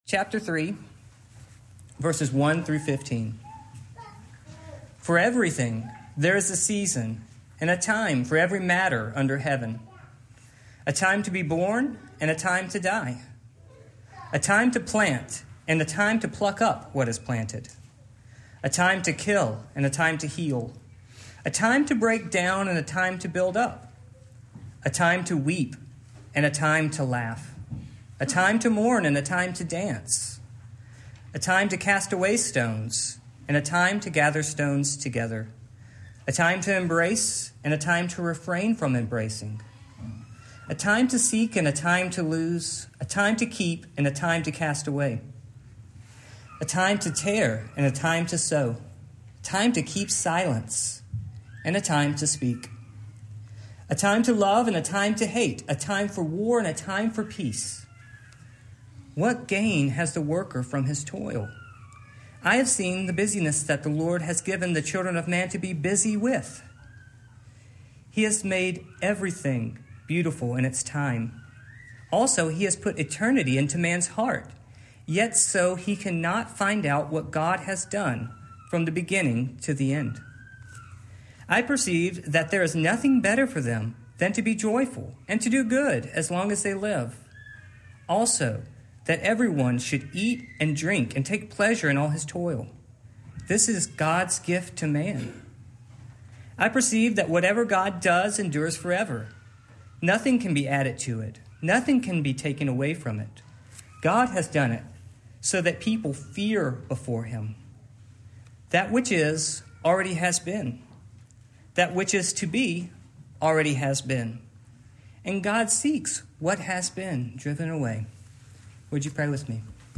Ecclesiastes 3:1-15 Service Type: Morning Main point